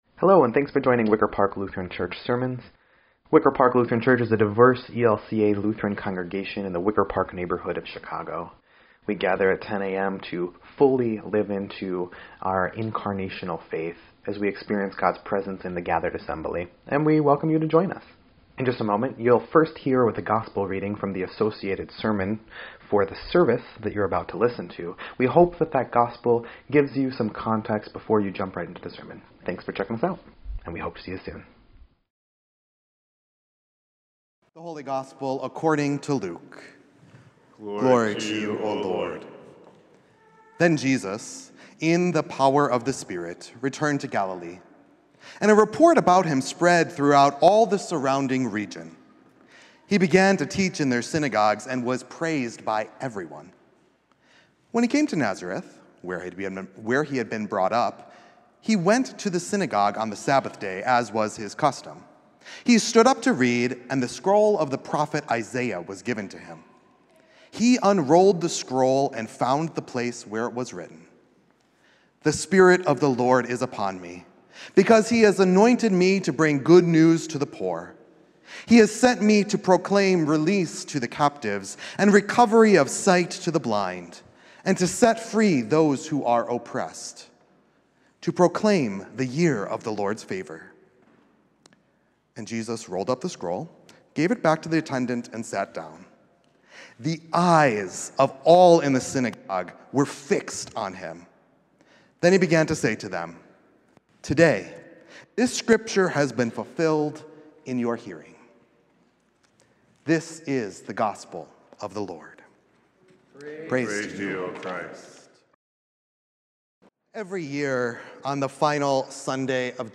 1.26.25-Sermon_EDIT.mp3